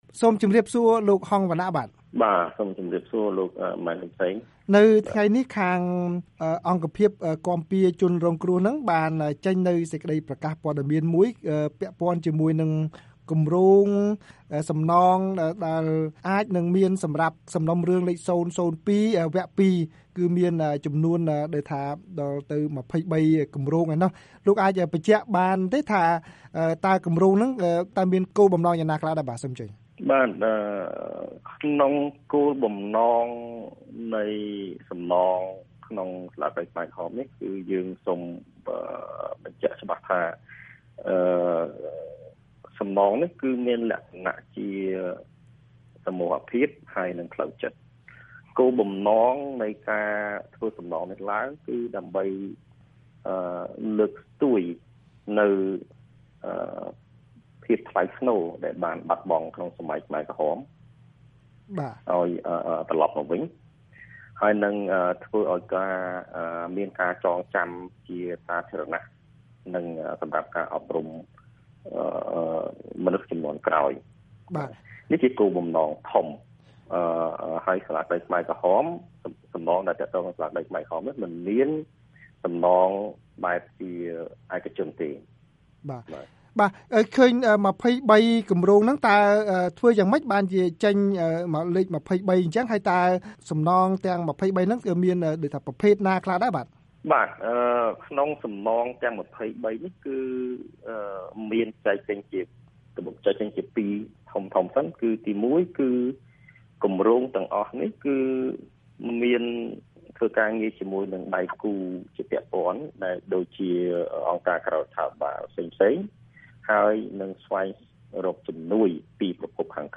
បទសម្ភាសន៍ VOA៖ អង្គភាពគាំពារជនរងគ្រោះនៃសាលាក្តីខ្មែរក្រហមដាក់គម្រោង២៣ជាសំណងក្នុងសំណុំរឿង០០២/០២